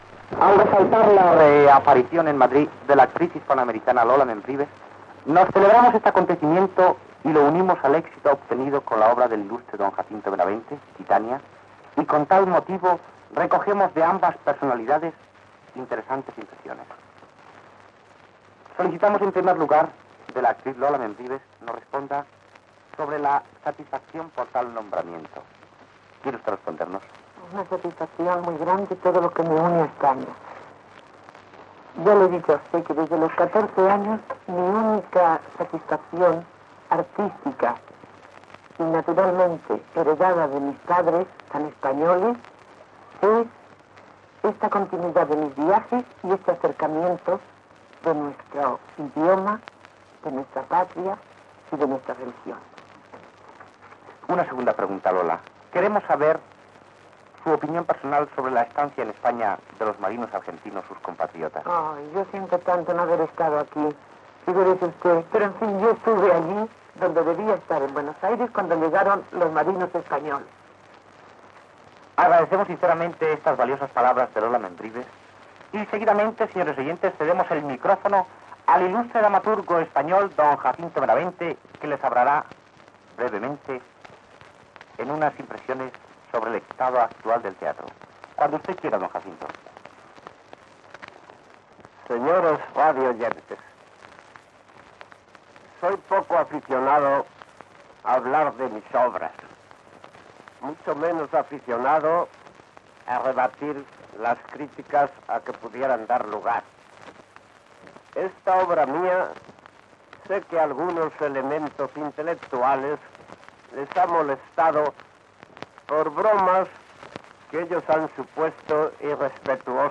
Entrevista a l'actriu argentina Lola Membrives que actuava a Madrid i paraules de l'escriptor Jacinto Benavente, premi Nobel de literarura l'any 1922, sobre la seva obra teatral "Dania"
Extret del programa "El sonido de la historia", emès per Radio 5 Todo Noticias el 10 de novembre de 2012